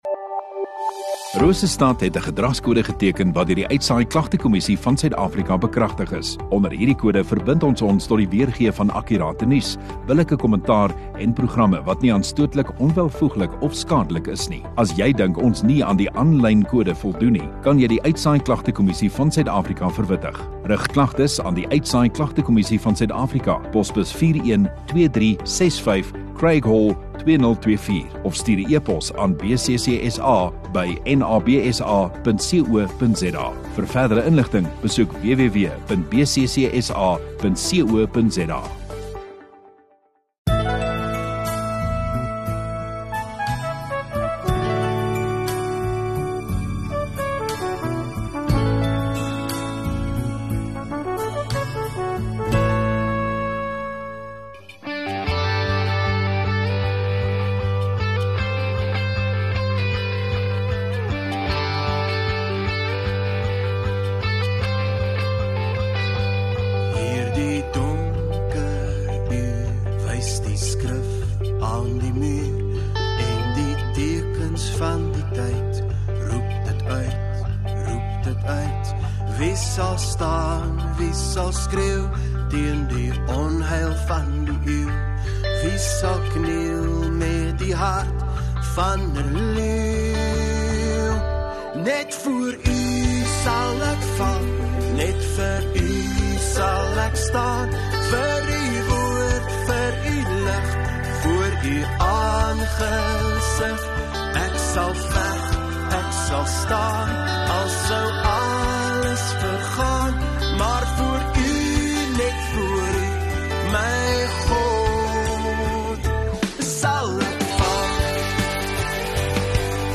8 Jul Maandag Oggenddiens